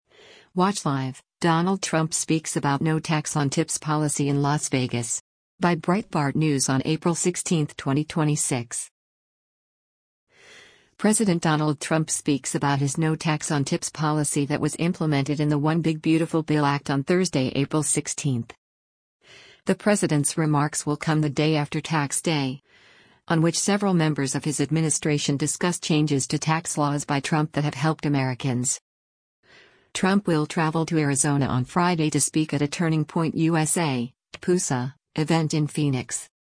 President Donald Trump speaks about his No Tax on Tips policy that was implemented in the One Big Beautiful Bill Act on Thursday, April 16.